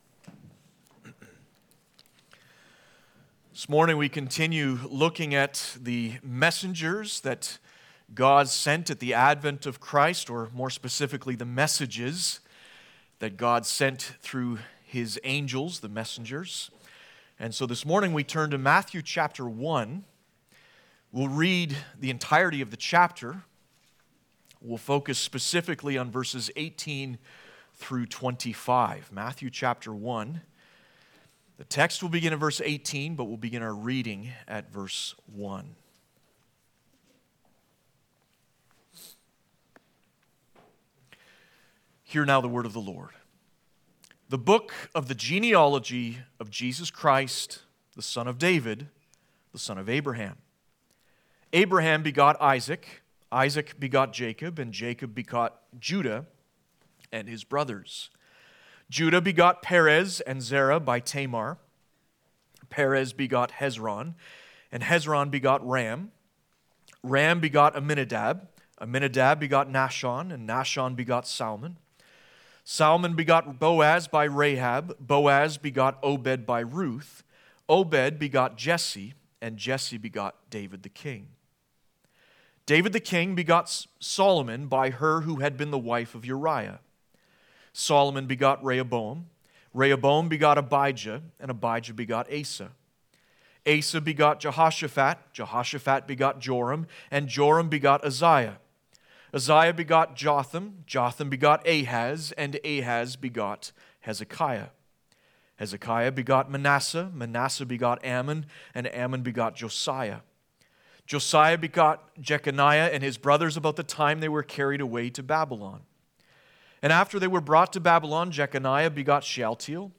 Passage: Matthew 1:18-25 Service Type: Sunday Morning